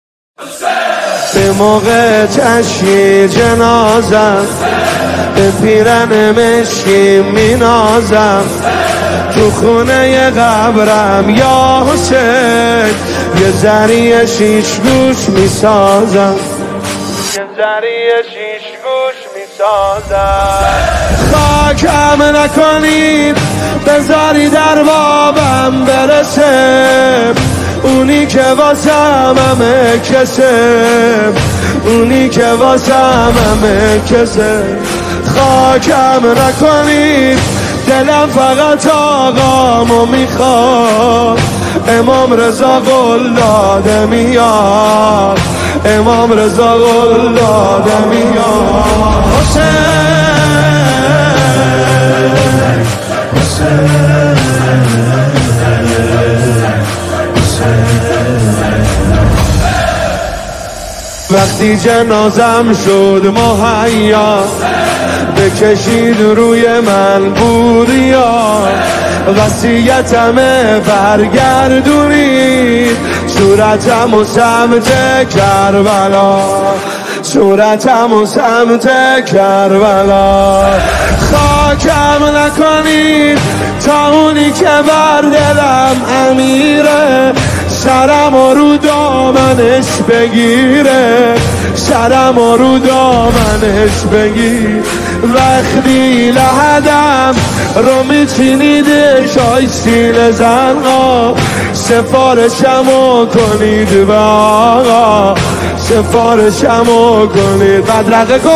ریمیکس بیس دار نوحه
برچسب هاپرطرفدار ، تک آهنگ ، نوحه